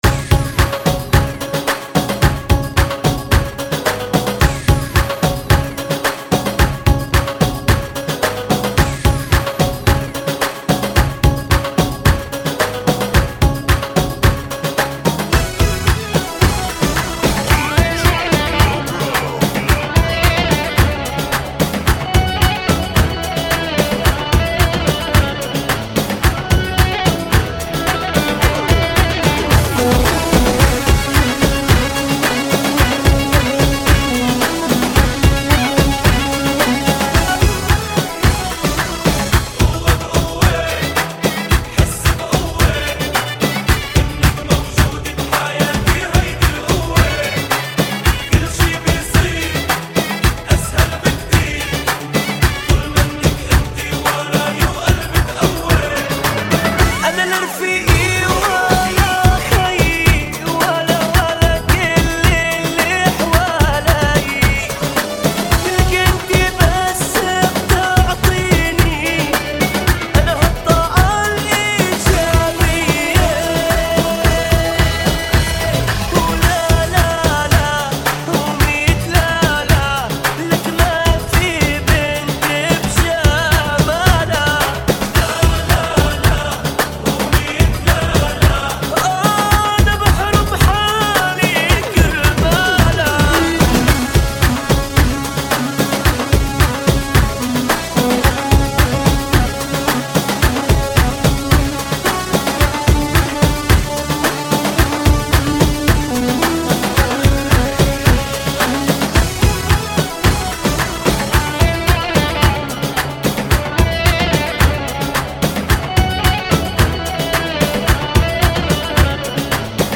[ 110 bpm ] FunKy